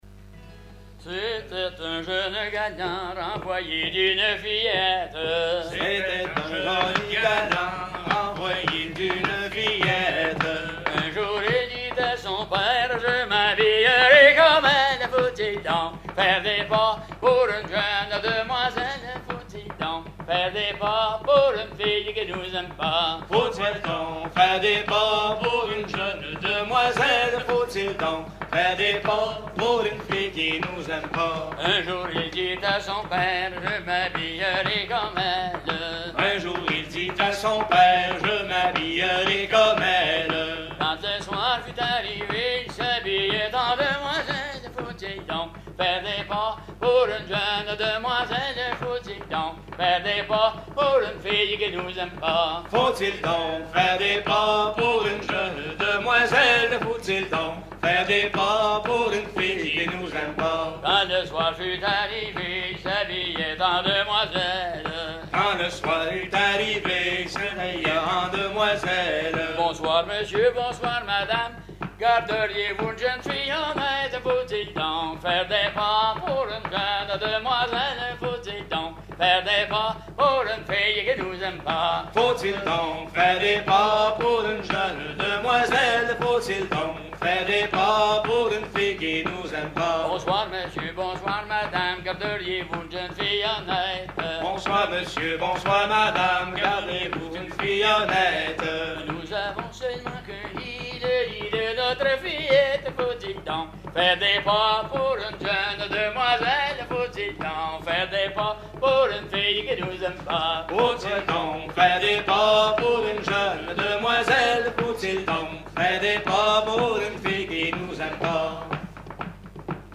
Genre strophique
Concert à la ferme du Vasais
Pièce musicale inédite